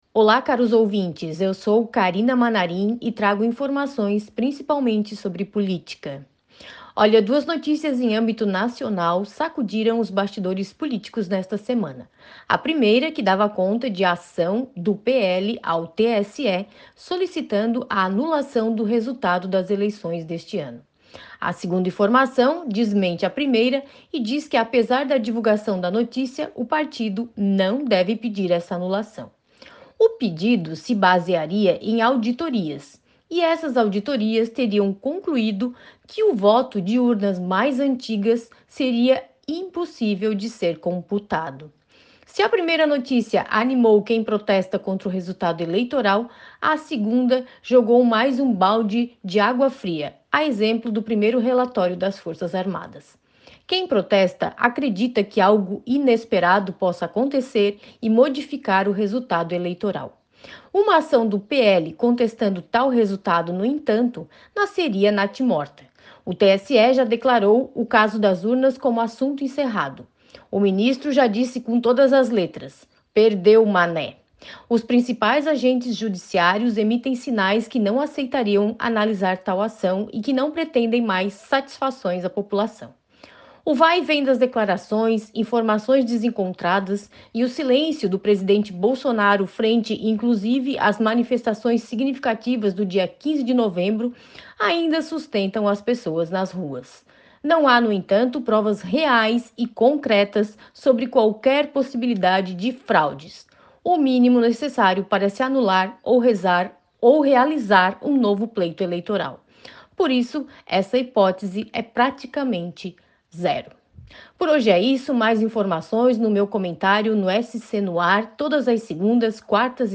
A jornalista destaca sobre as manifestações e as dúvidas que ainda permanecem após o segundo turno das eleições deste ano